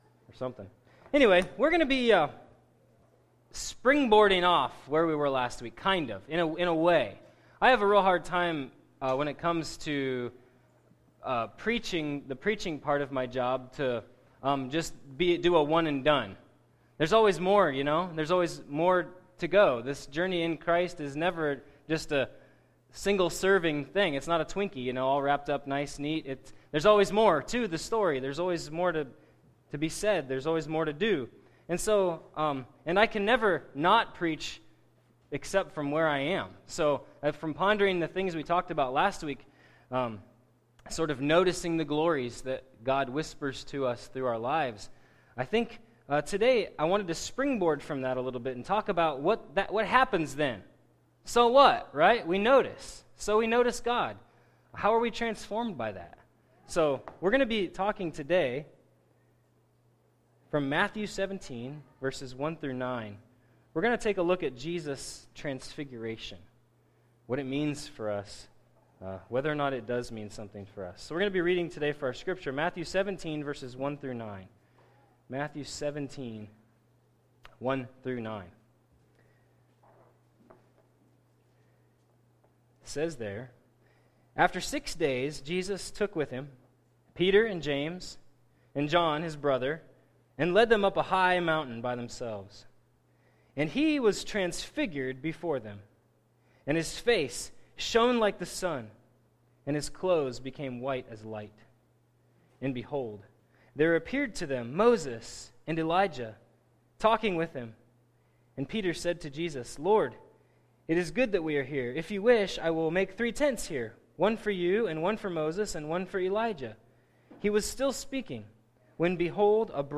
Message for Sunday, July 16, 2017 entitled Busting Out.